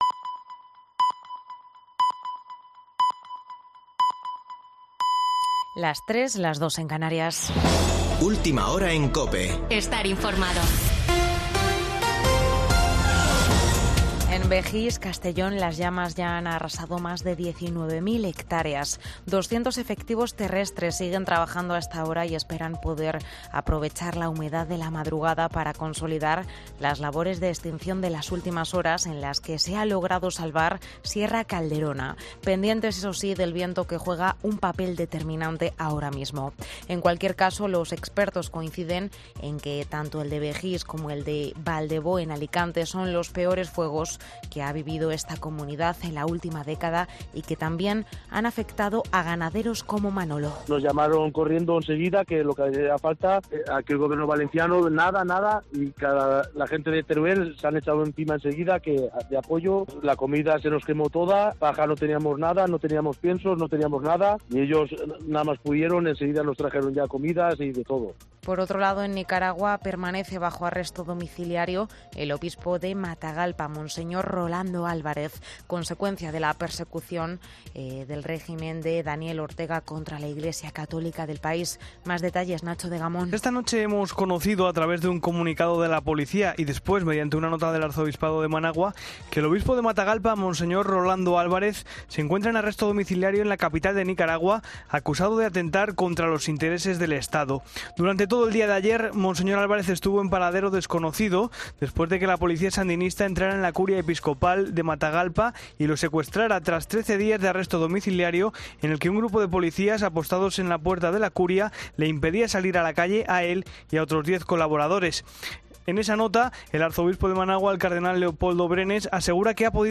Boletín de noticias de COPE del 20 de agosto de 2022 a las 03.00 horas